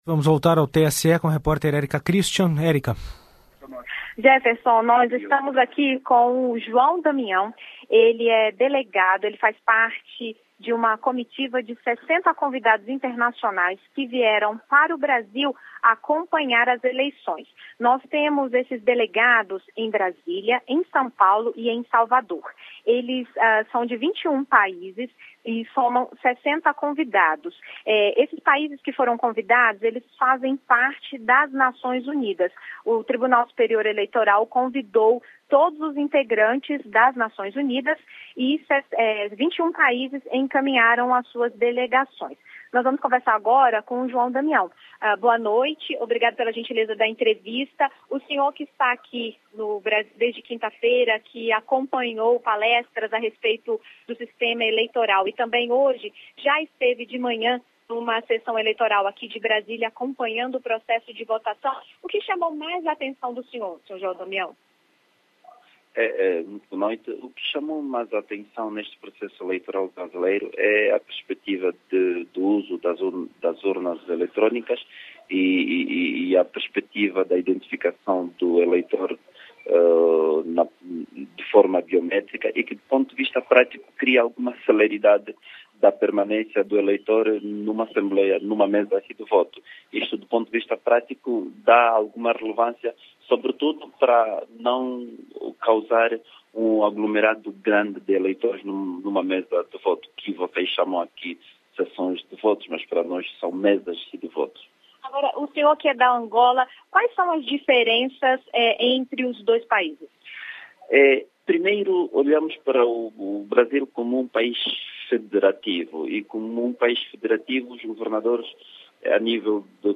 TSE: Entrevista com observador internacional das eleições